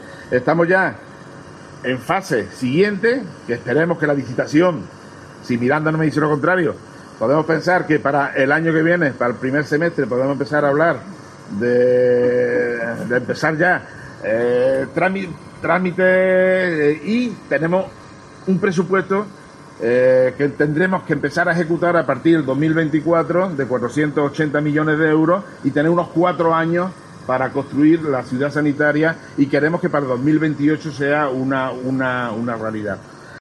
Jesús Aguirre, Consejero de Salud, asegura que las obras de la Ciudad Sanitaria comenzarán en 2024